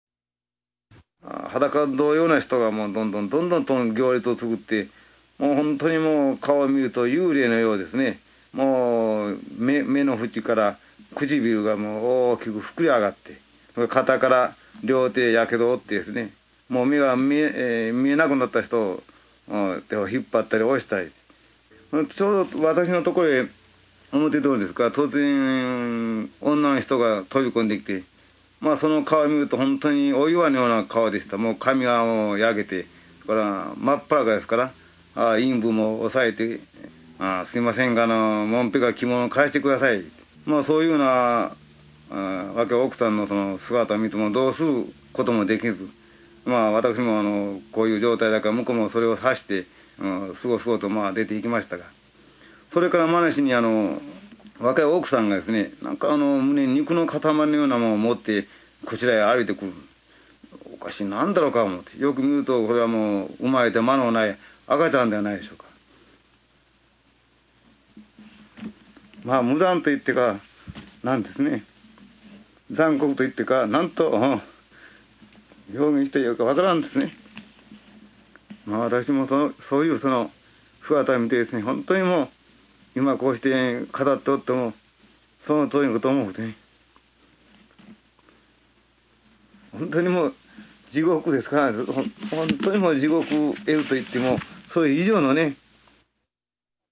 広島の被爆者の声（２） （２枚目のＣＤ の１１から２０まで）